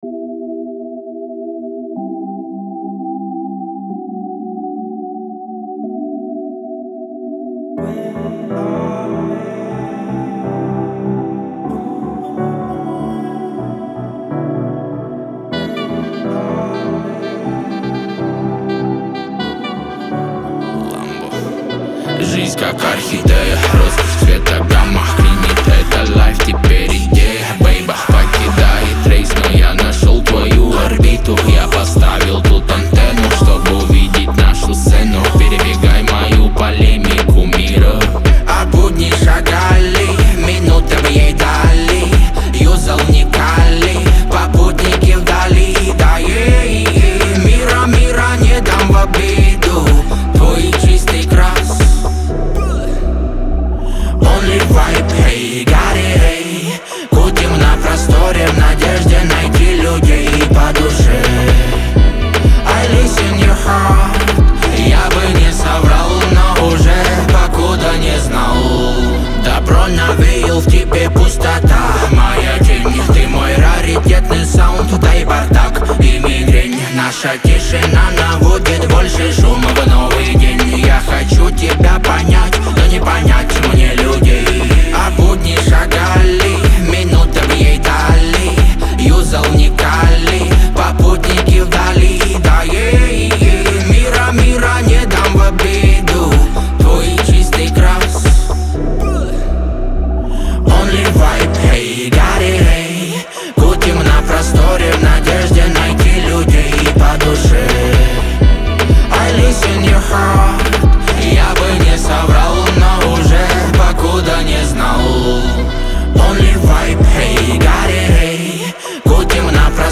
Категории: Русские песни, Рэп и хип-хоп.